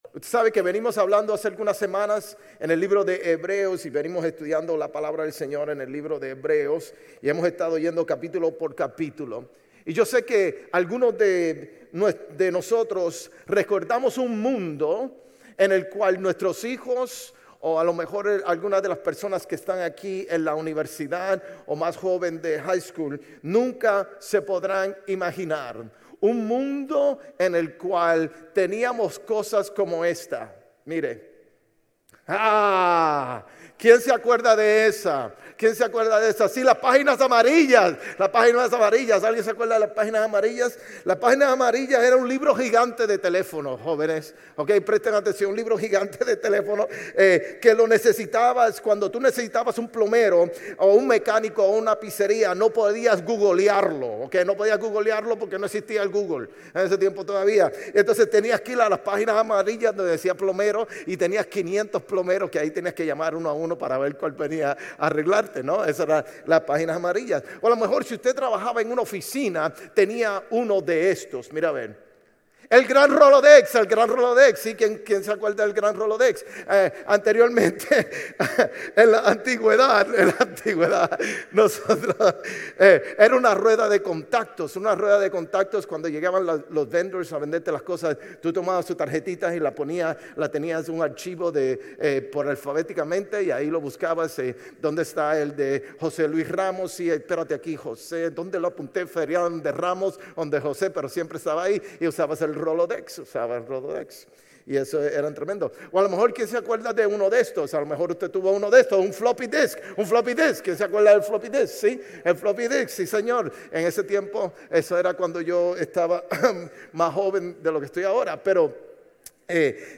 Sermones Grace Español 11_23 Grace Espanol Campus Nov 24 2025 | 00:39:14 1x 00:00 / 00:39:14 Subscribe Share RSS Feed Share Link Embed